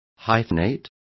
Complete with pronunciation of the translation of hyphenating.